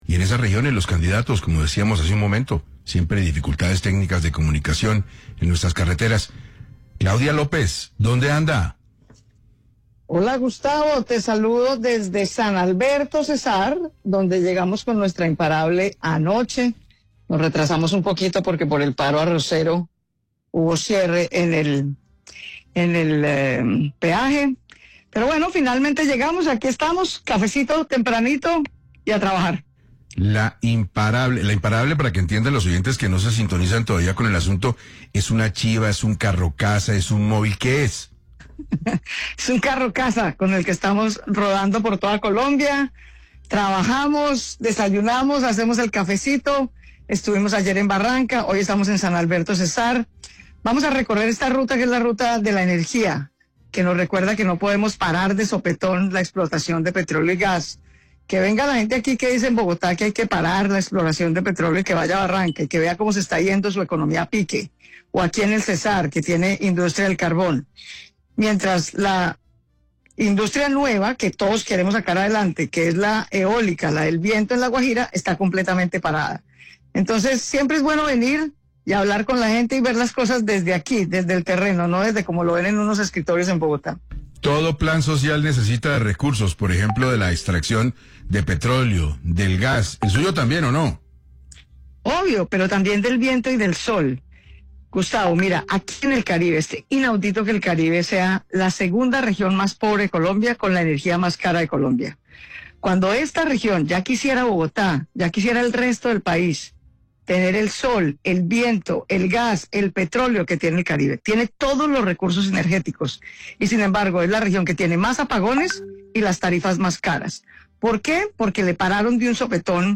Durante la entrevista, Claudia López presentó su plan social nacional, estructurado en torno a tres grandes pilares: educación superior, vivienda digna y cuidados para mujeres.